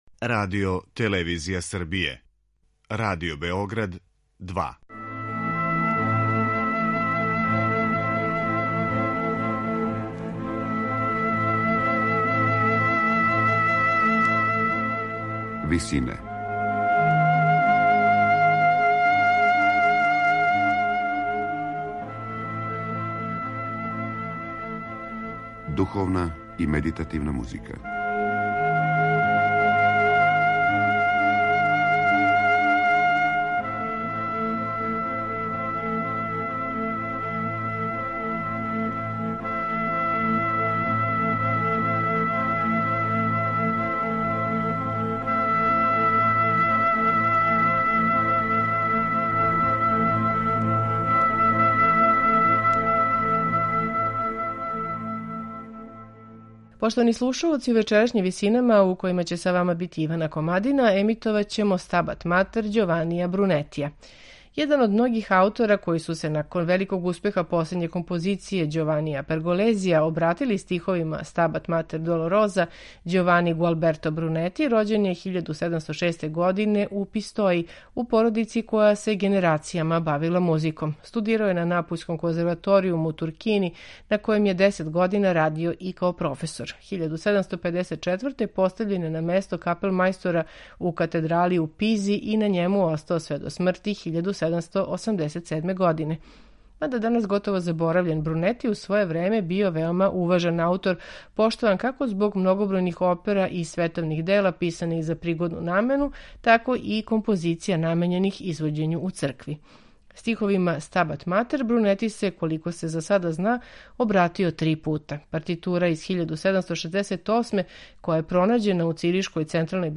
сопран
контратенор
ансамбл